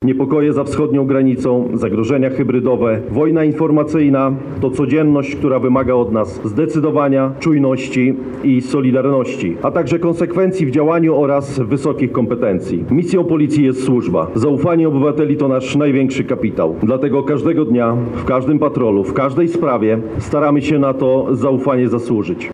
Wojewódzkie Obchody Święta Policji odbyły się w Lublinie. Podczas uroczystości wręczono medale i awanse, a także odbył się pokaz musztry paradnej w wykonaniu Orkiestry Reprezentacyjnej Policji.